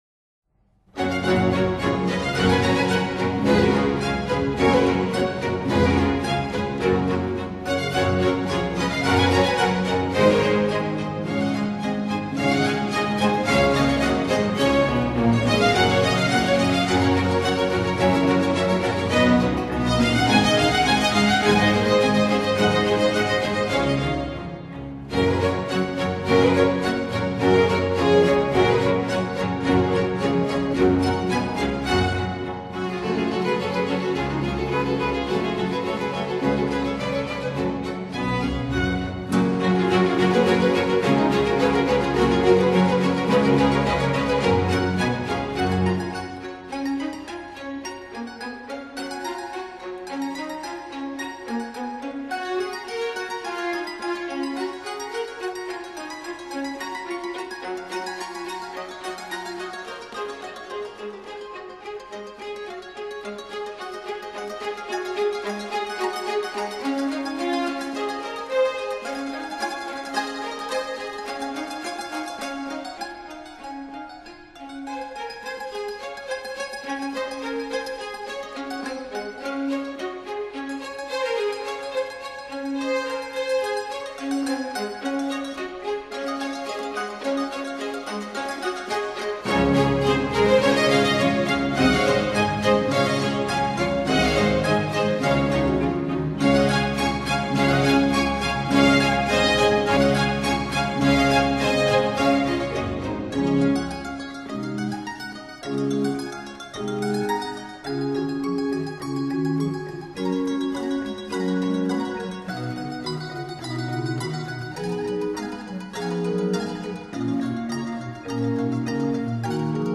In G major, for 2 Mandolines.